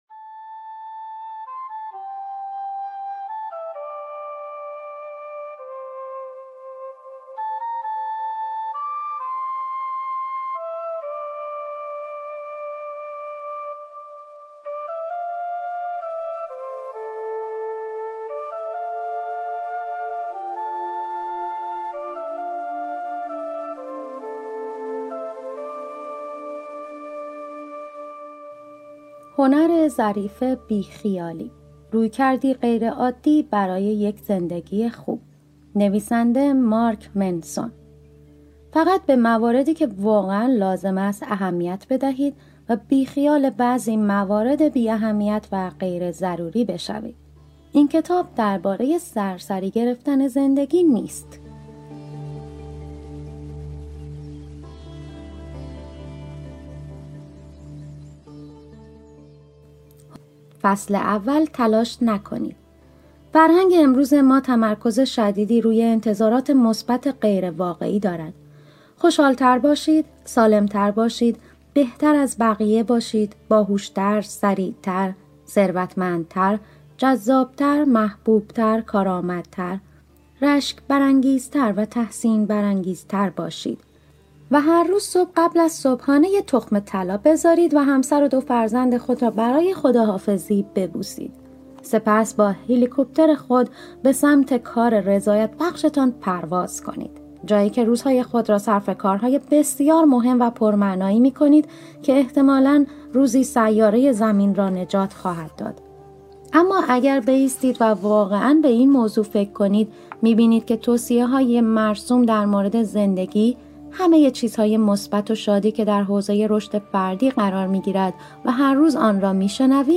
کتاب صوتی هنر ظریف بی خیالی Podcast - 01 - تلاش نکنید | Free Listening on Podbean App